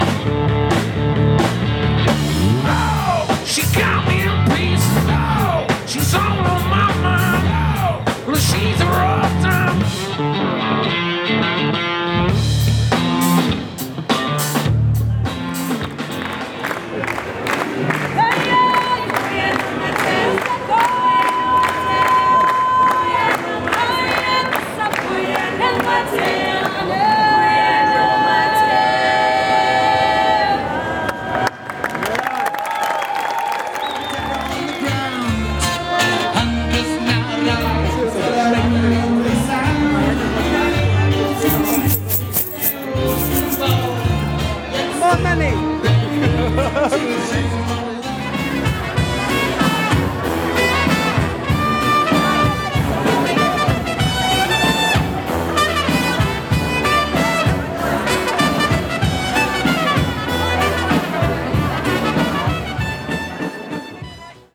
Buskers Bern medley